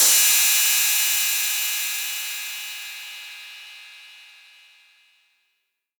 808CY_3_TapeSat_ST.wav